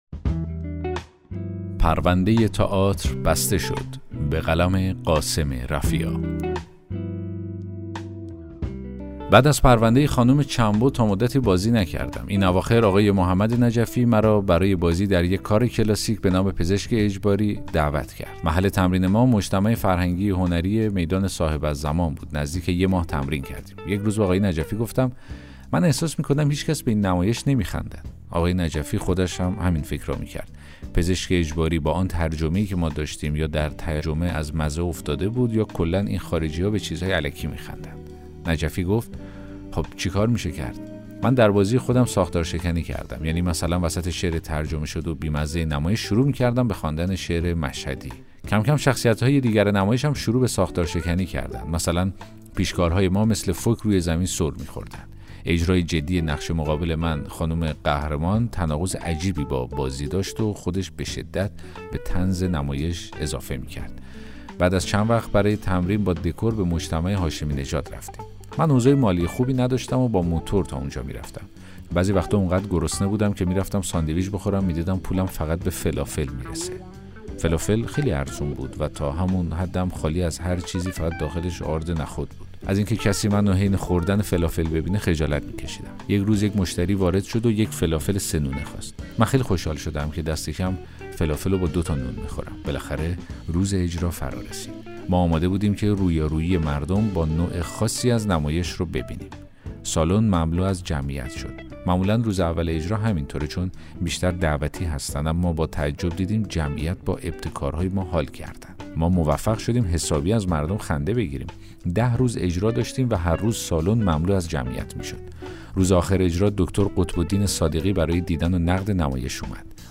داستان صوتی: پرونده تئاتر بسته شد